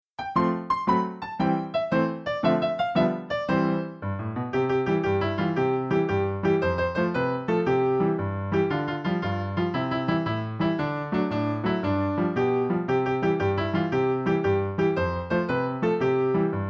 Listen to a sample piano instrumental
Piano Solo